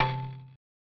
Marimba.wav